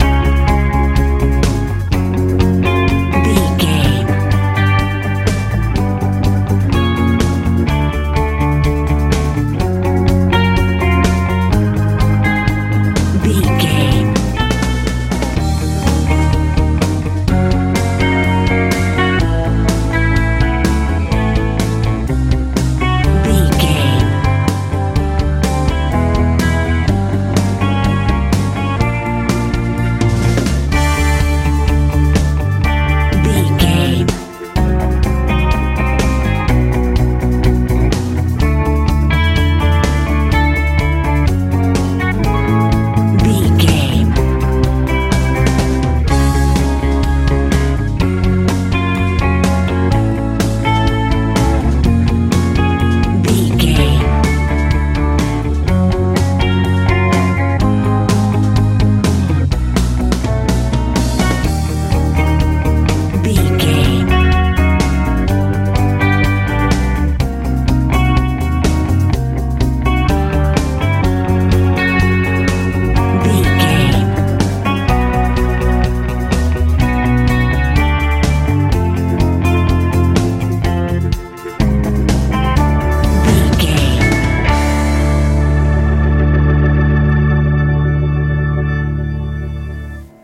new wave feel
Ionian/Major
C♯
bouncy
dreamy
organ
bass guitar
drums
electric guitar
80s
optimistic
lively